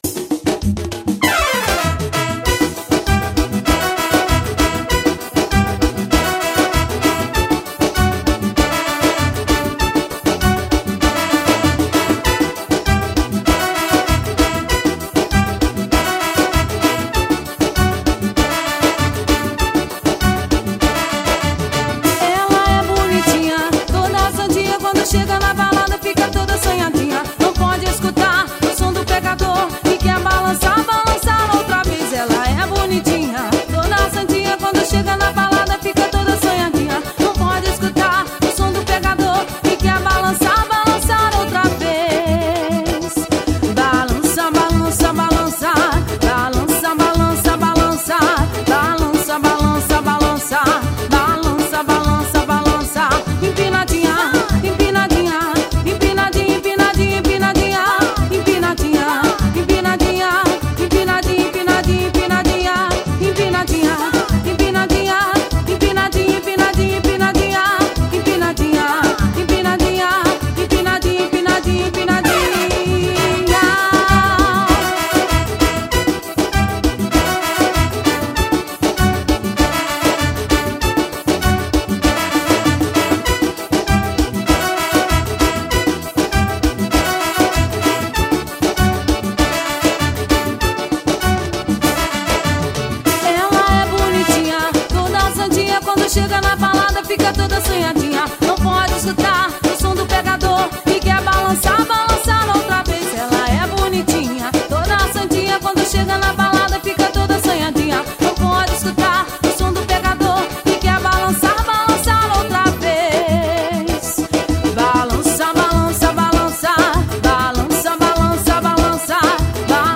forro pegado.